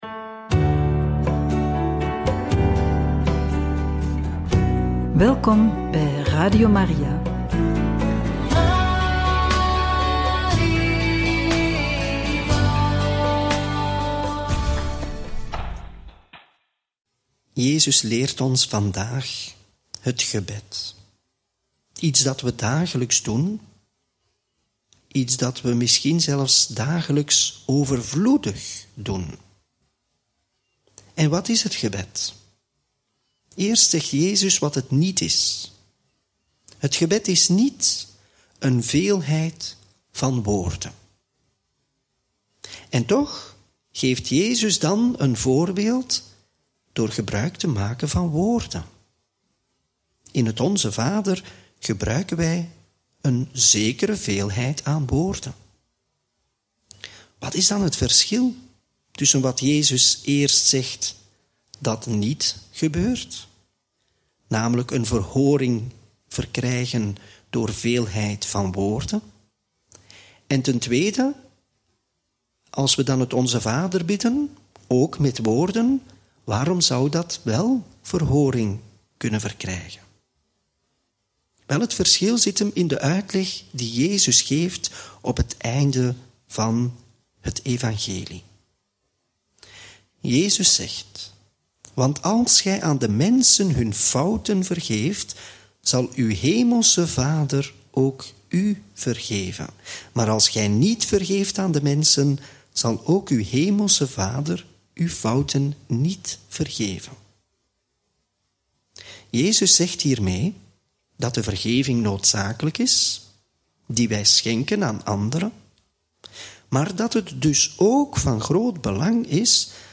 Homilie bij het Evangelie van donderdag 20 juni 2024 (Mt 6, 7-15)